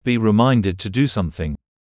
28.be reminded to do something /biː rɪˈmaɪndɪd tuː duː ˈsʌmθɪŋ/ (v.phr): được nhắc nhở làm gì